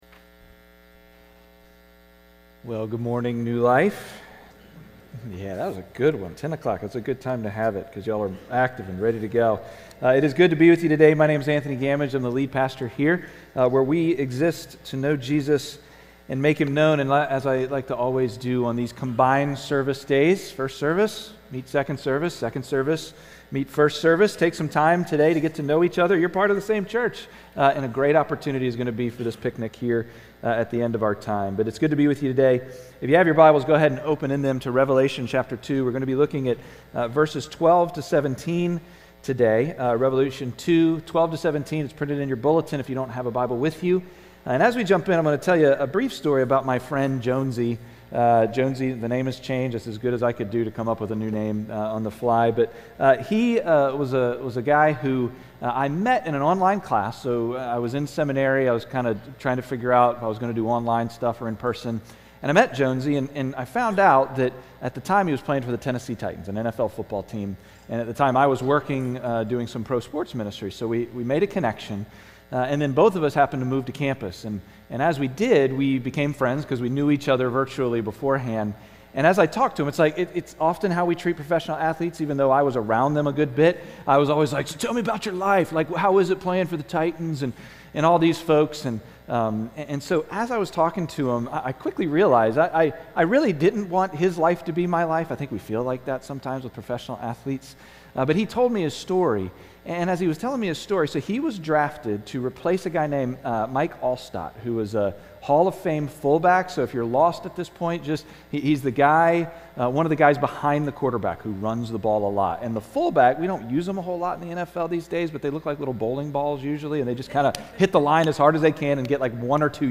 Sermon-52525.mp3